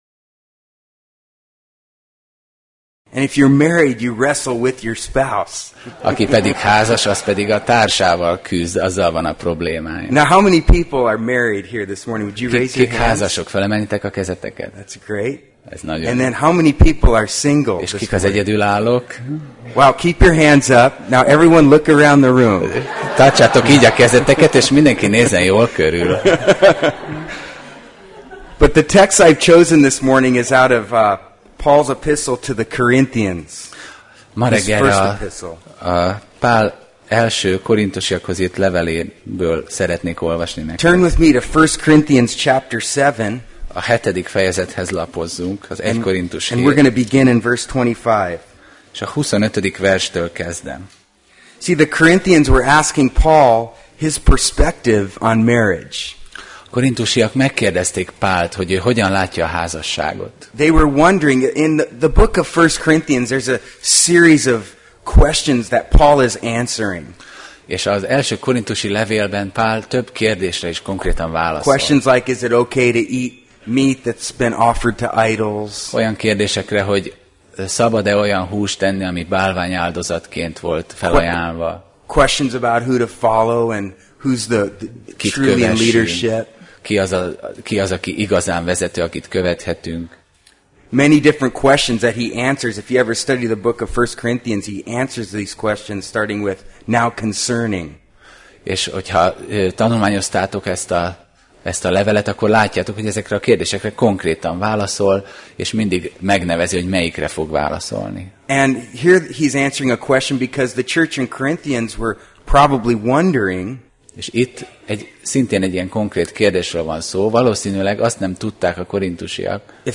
Tematikus tanítás
Alkalom: Vasárnap Reggel